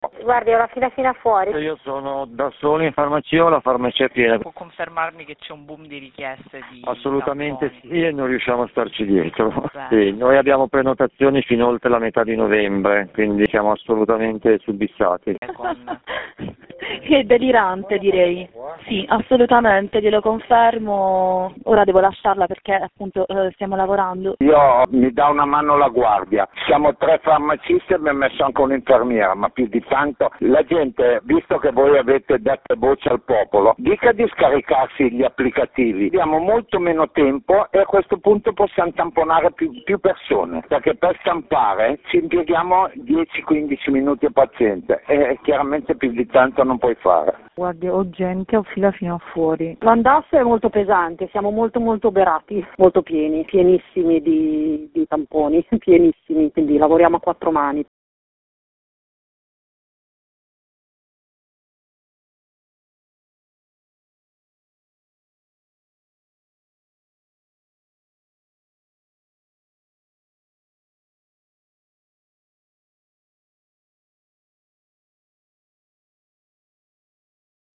Le voci dei farmacisti di turno